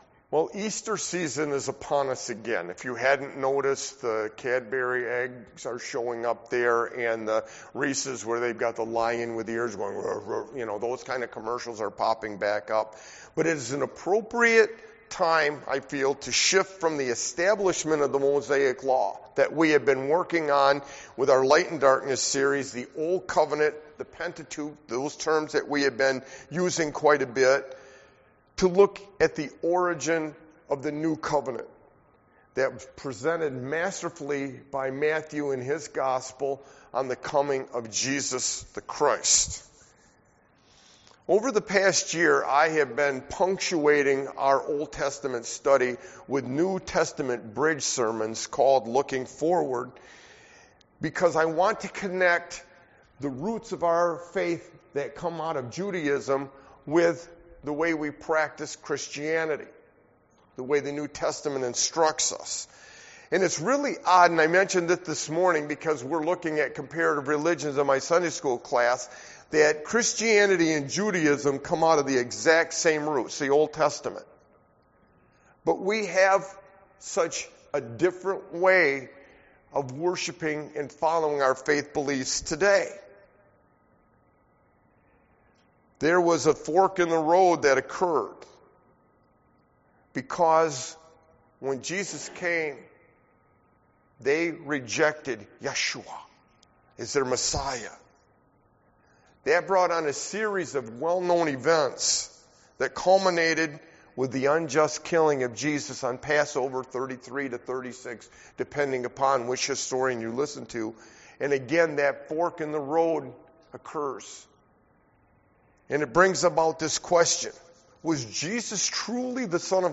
Matthew 21:1-11; Bridging our ongoing OT Light and Darkness sermon series from, the coming of Jesus to Jerusalem for Passover was the brightest of lights upon God’s Son. The traveling Jewish pilgrims from Galilee hailed him as “Hosanna”, while the religious authorities schemed in the background for a chance to permanently remove Christ as a threat to their authority.